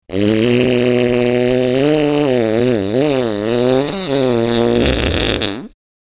32. Пук королевского палача
puk-korolevskogo-palacha.mp3